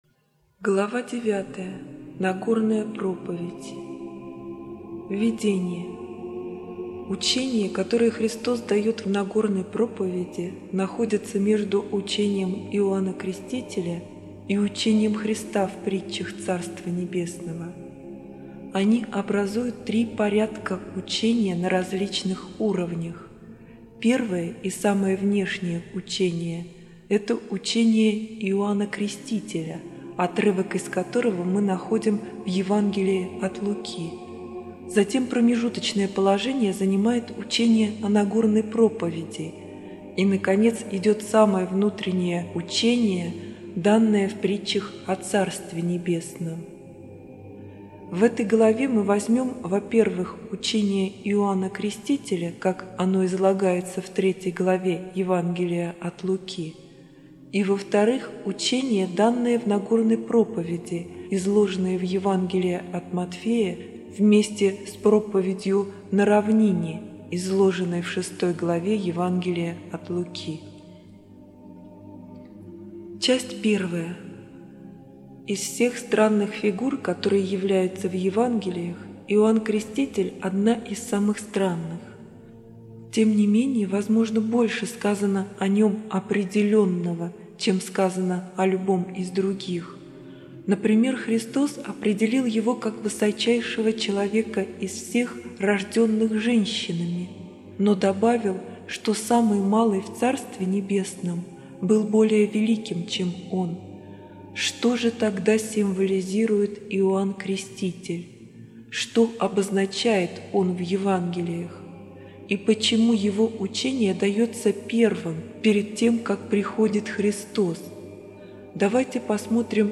Для улучшения восприятия чтение сопровождается специально подобранной по сути материала классической музыкой и православным песнопением.